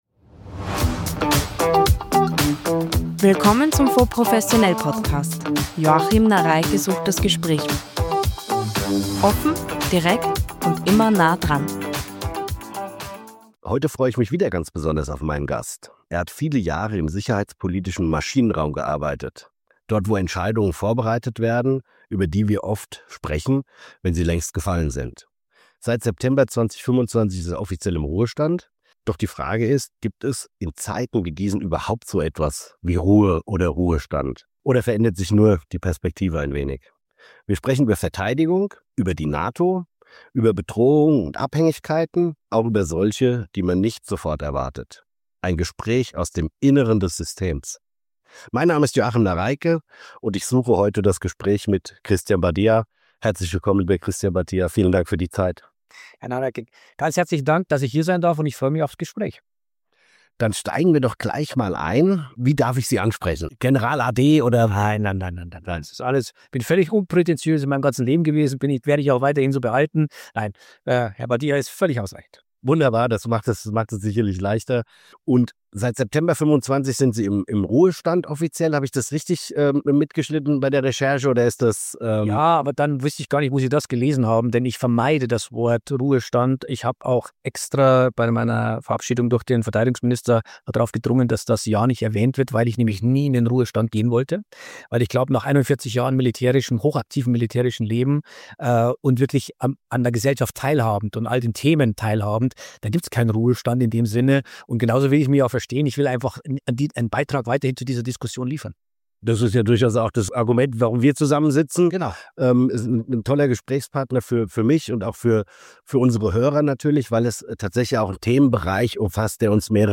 Ein Gespräch aus dem Inneren des Systems